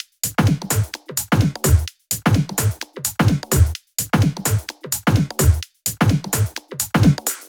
VFH3 128BPM Wobble House Kit